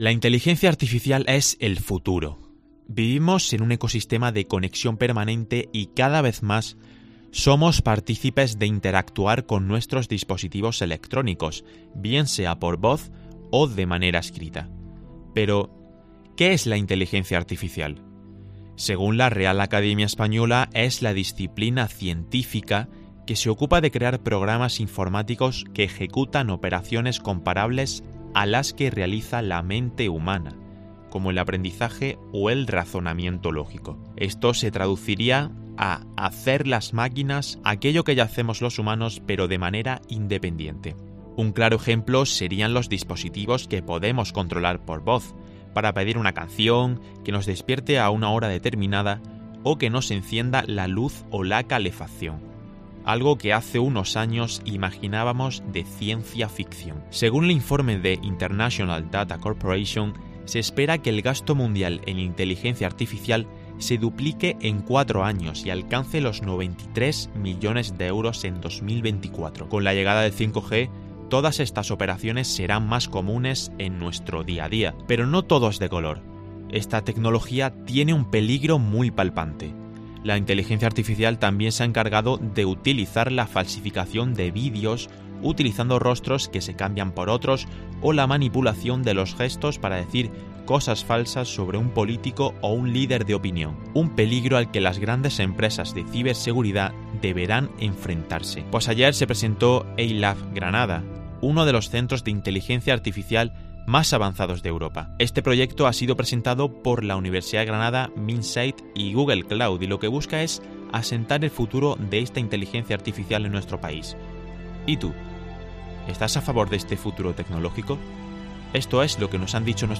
Esto es lo que nos han dicho nuestros oyentes.